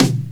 • Acoustic Tom One Shot D Key 21.wav
Royality free tom drum tuned to the D note. Loudest frequency: 1482Hz
acoustic-tom-one-shot-d-key-21-hHE.wav